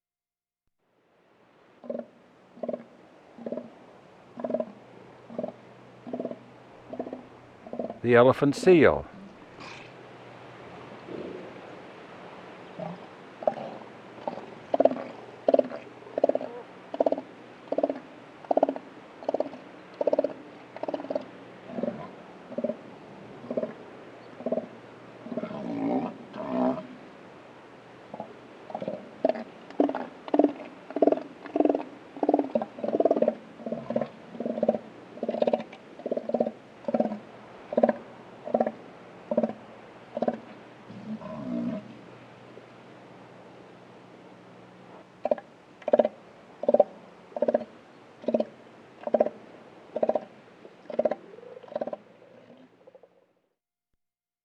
Звук где слон трубит долгий